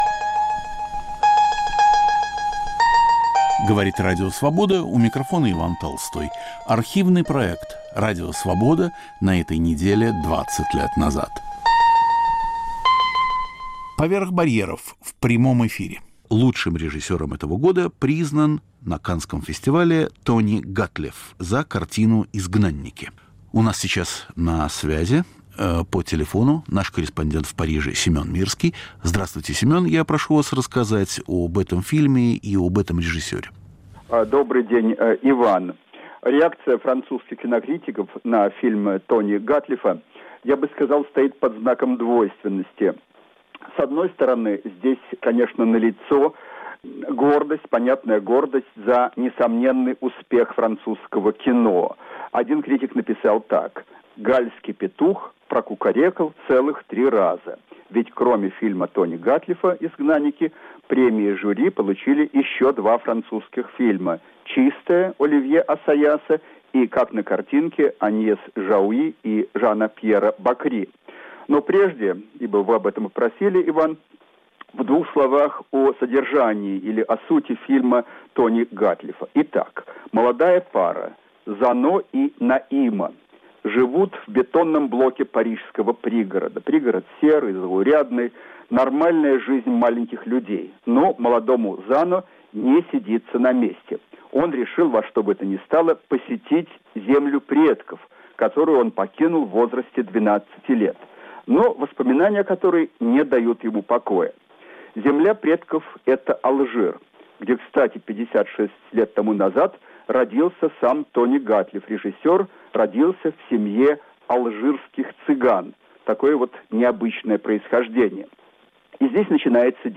"Поверх барьеров" в прямом эфире. О режиссере Тони Гатлифе и его фильме "Изгнанники"
О лучшем режиссере 2004 года и его фильме в жанре роуд-муви. Итоги Каннского фестиваля. В программе участвуют кинокритики и корреспонденты из Парижа, Нью-Йорка и Москвы.